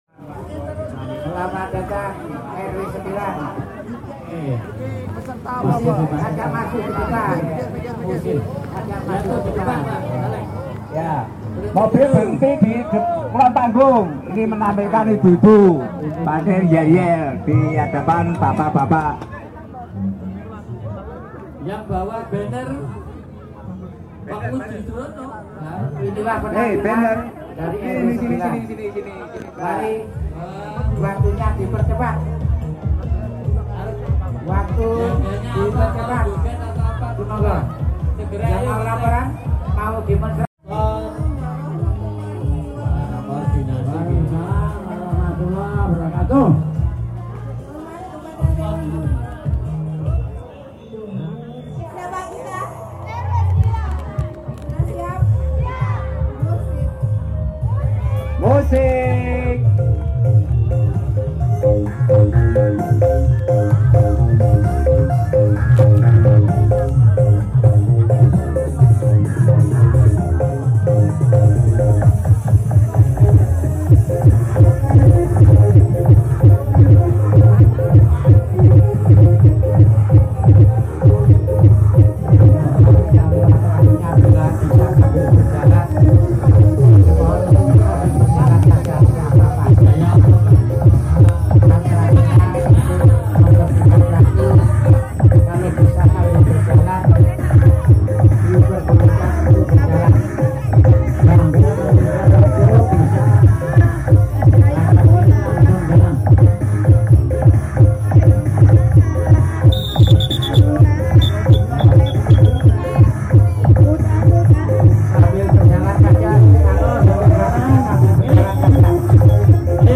PESERTA KARNAVAL DESA BERO TRUCUK